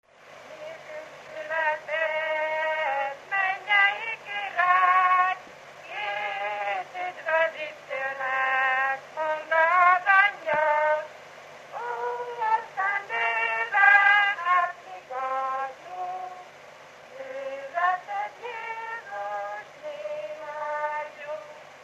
Erdély - Brassó vm. - Tatrang
Gyűjtő: Domokos Pál Péter
Stílus: 7. Régies kisambitusú dallamok
Kadencia: 1 (5) 5 1 8